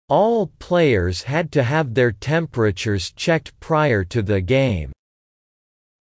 【ややスロー・スピード】